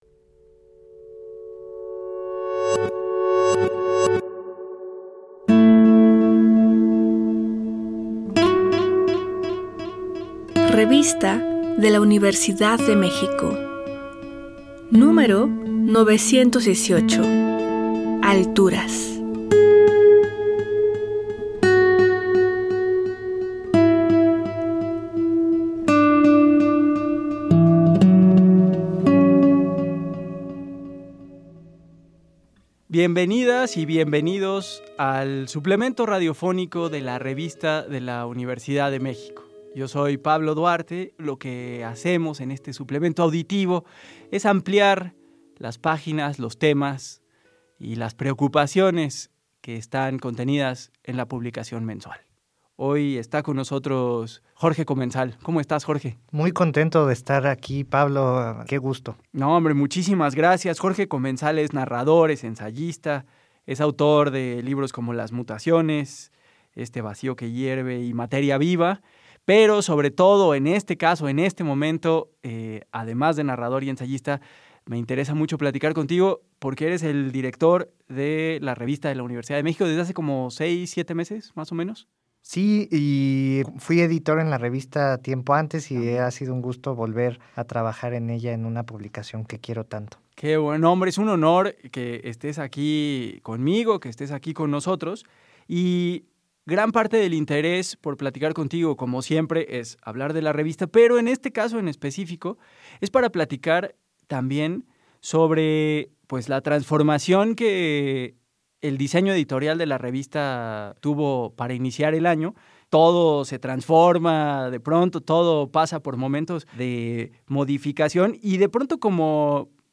Cargar audio Este programa es una coproducción de la Revista de la Universidad de México y Radio UNAM. Fue transmitido el jueves 20 de marzo de 2025 por el 96.1 FM.